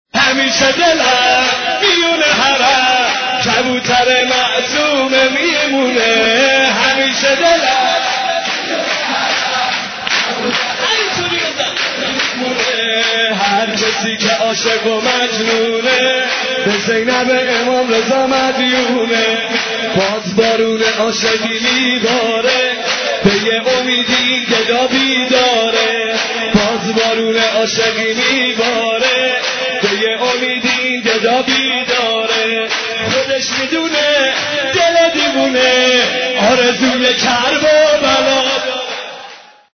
زنگ موبایل
رینگتون مذهبی